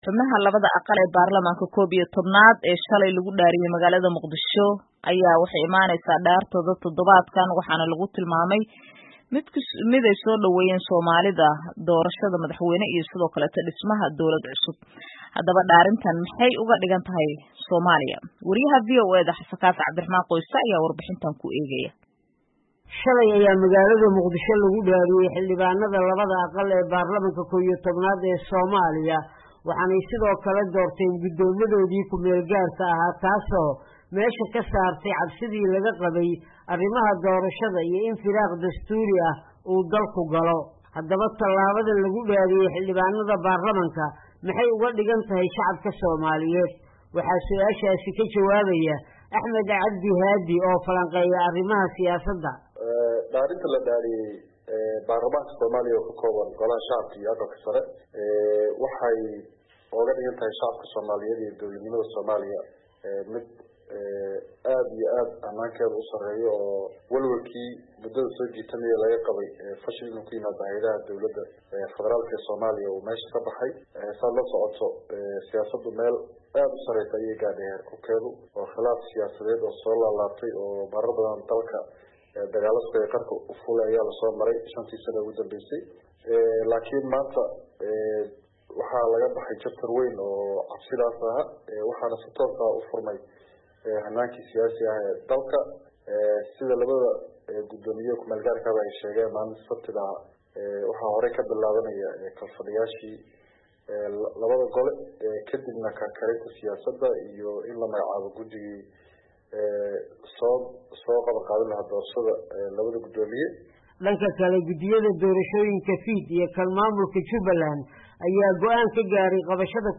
Muqdisho —